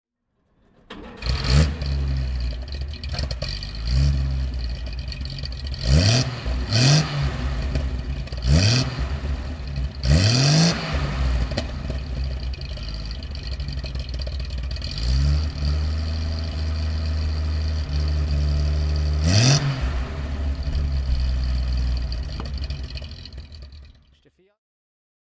BMW 316 TC1 Baur Cabriolet (1978) - Starten und Leerlauf